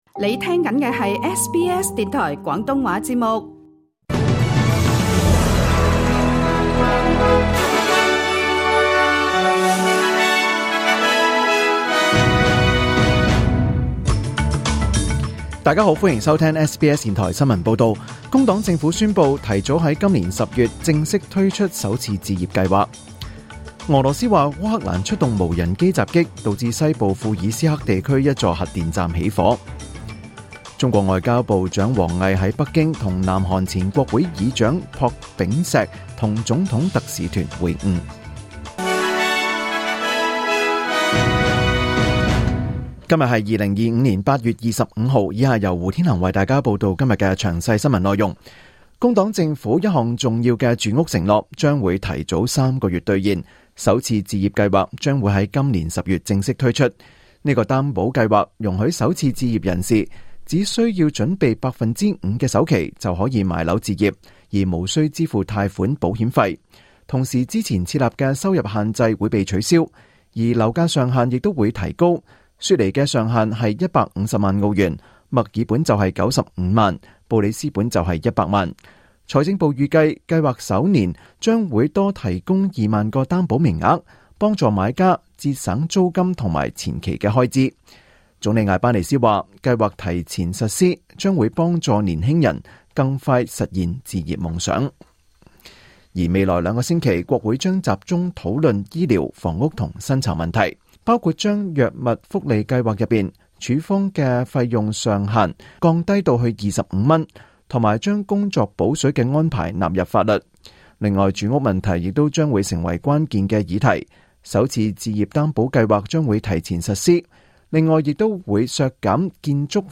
2025 年 8 月 25 日 SBS 廣東話節目詳盡早晨新聞報道。